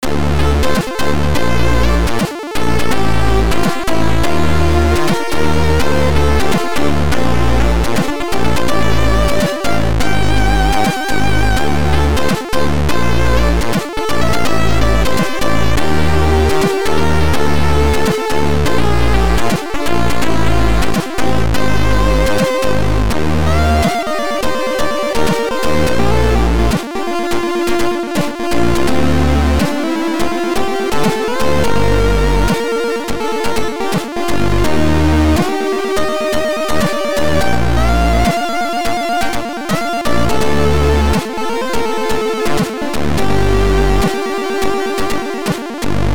chip
Type AHX v2 Tracker